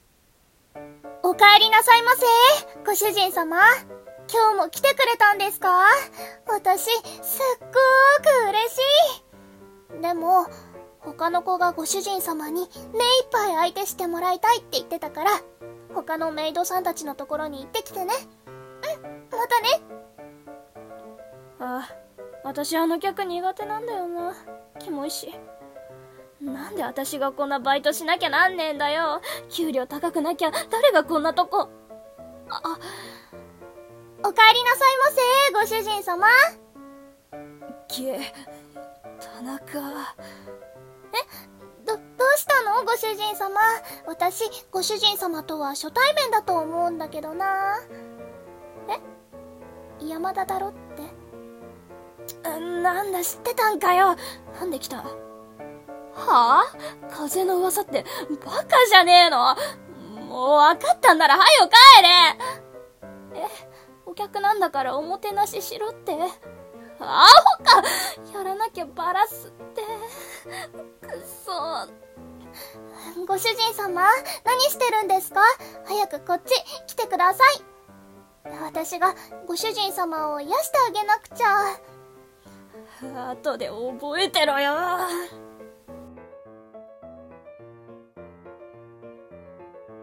【一人声劇台本】メイド喫茶のメイド男子(女子)の憂鬱